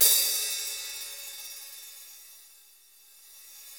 CYM X13 HA0A.wav